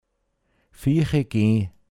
pinzgauer mundart
nach vorne gehen viichegee(n)